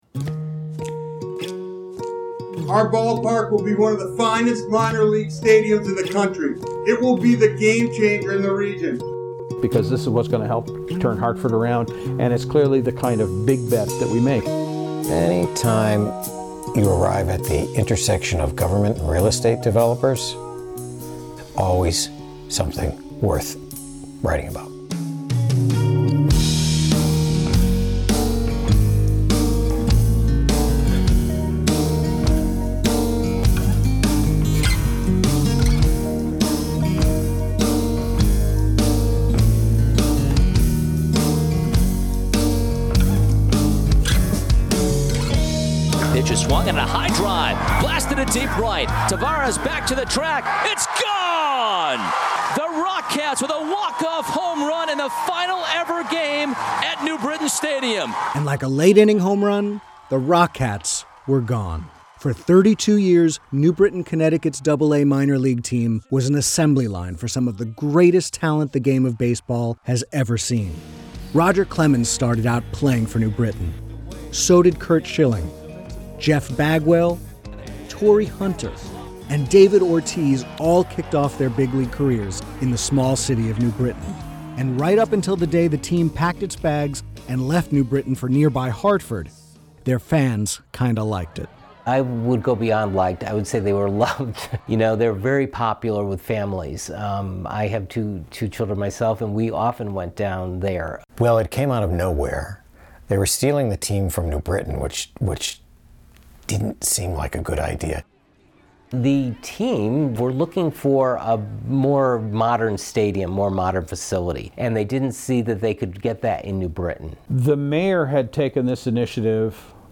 Music: "Counting Sundays", "Thankful (outro)" by Pyrn and "Bad Scene" by Poddington Bear.